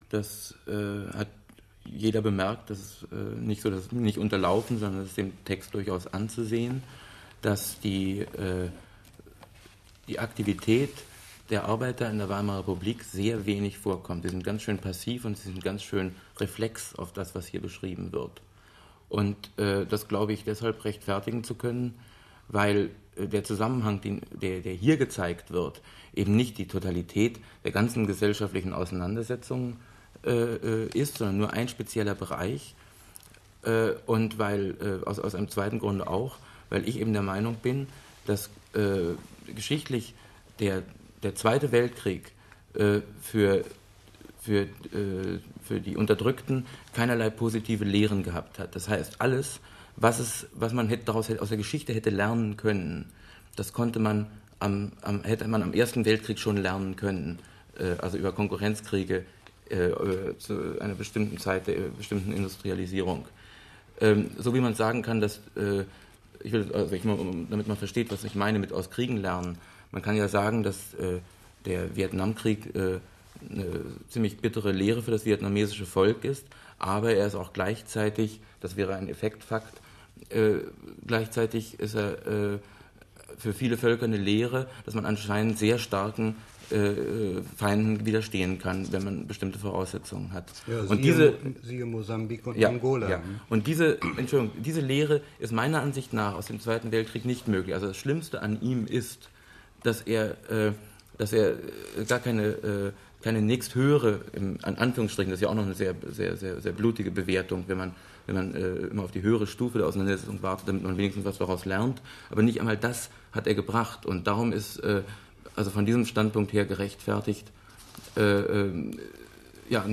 Hier ein kurzer Auszug aus der einstündigen Diskussion.